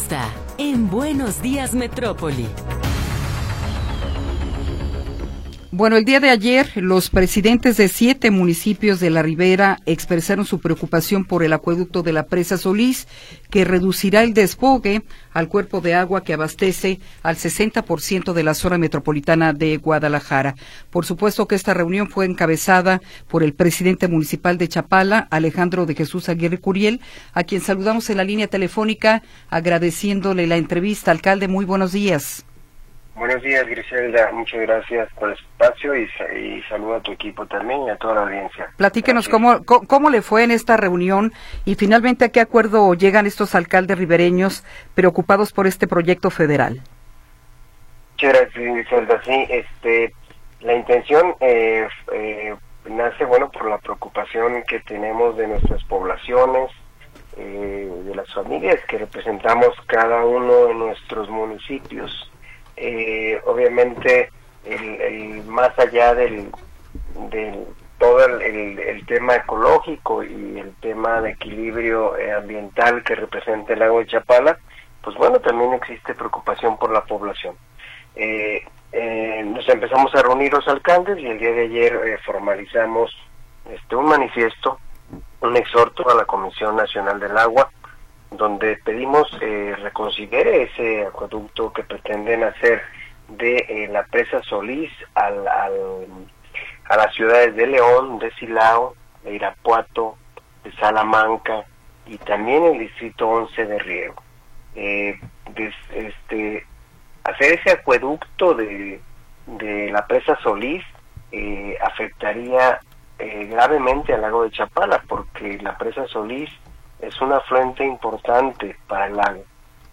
Entrevista con Alejandro de Jesús Aguirre Curiel
Alejandro de Jesús Aguirre Curiel, presidente municipal de Chapala, nos habla sobre el manifiesto contra el Acueducto Solís firmado por los alcaldes ribereños.